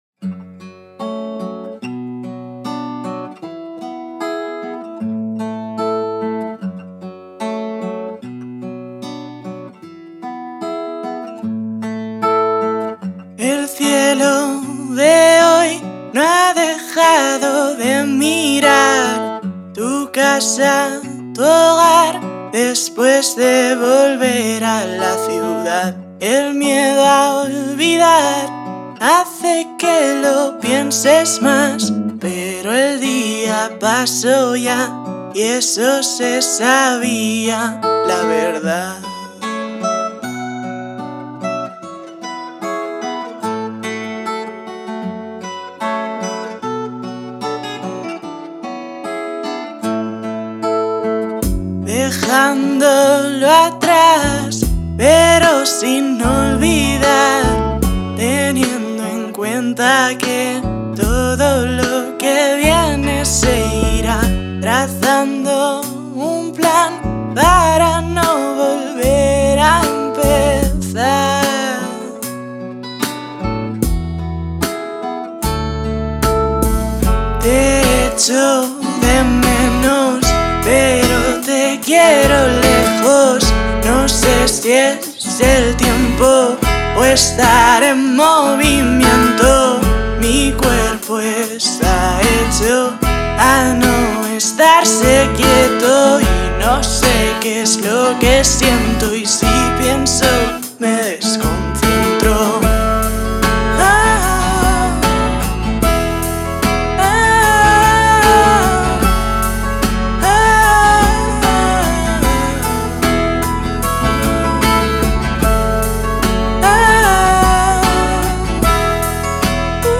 Canciones estilo pop indie
guitarra y teclado